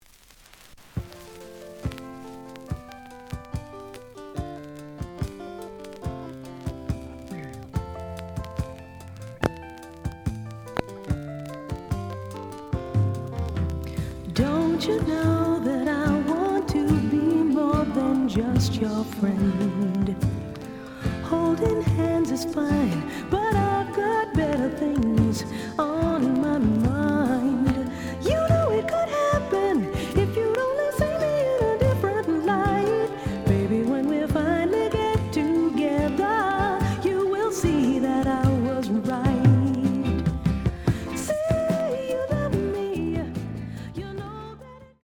The audio sample is recorded from the actual item.
●Genre: Soul, 70's Soul
Some noise on beginning of A side.